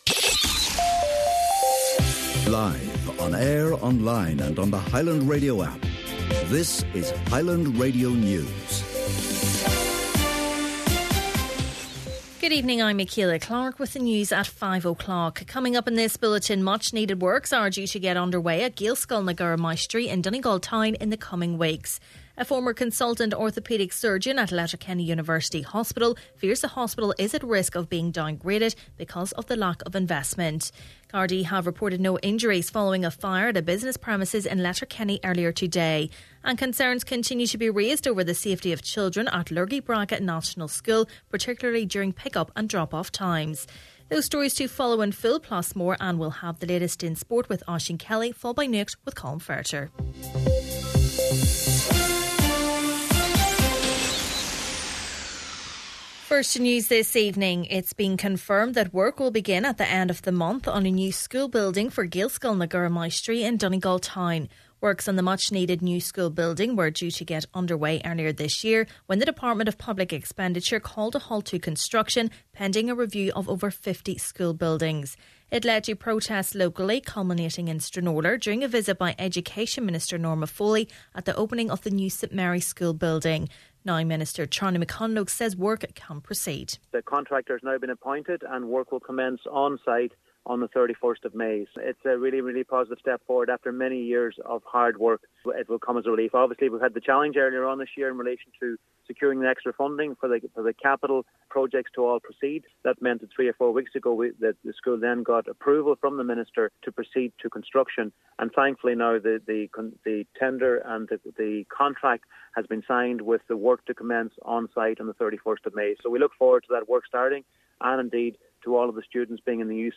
Main Evening News, Sport, Nuacht and Obituaries – Friday May 12th